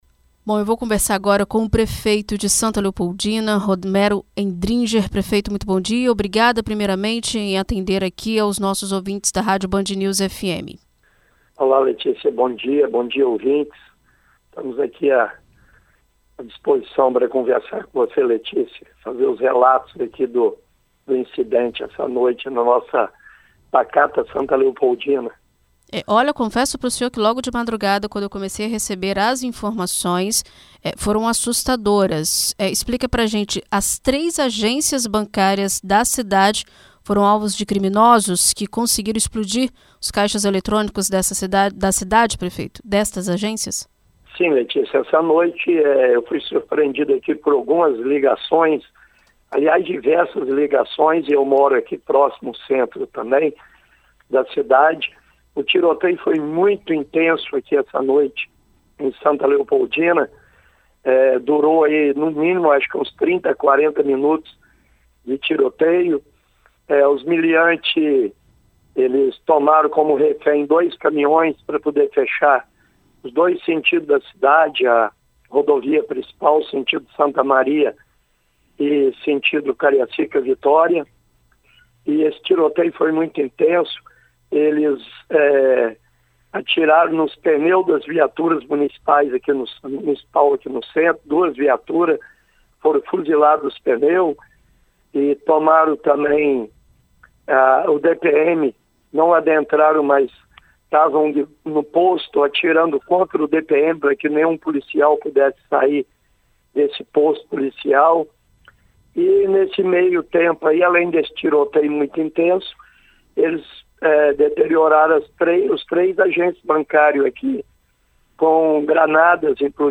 Em entrevista à BandNews FM Espírito Santo nesta sexta-feira (30), o prefeito do município, Romero Endringer, conversa sobre o ocorrido na cidade e detalha as primeiras informações sobre o trabalho de contenção dos estragos feitos pelos bandidos.
entrevista-prefeito-santa-leopoldina.mp3